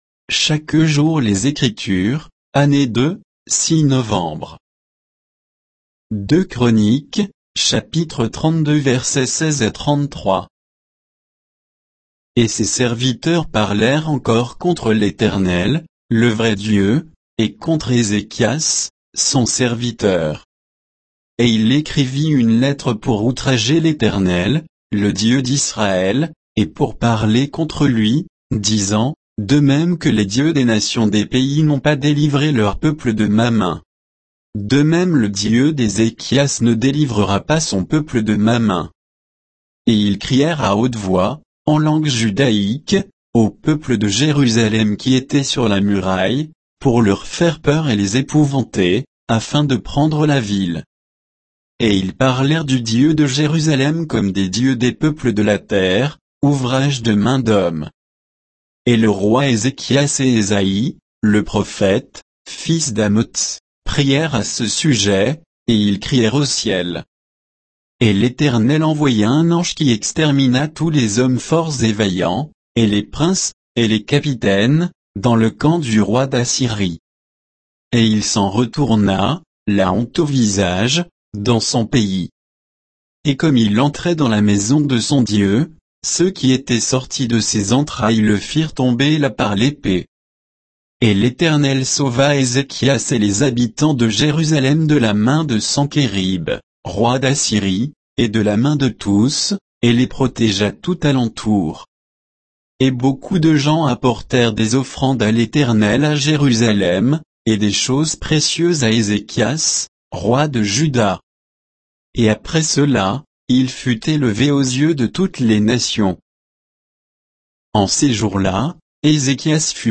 Méditation quoditienne de Chaque jour les Écritures sur 2 Chroniques 32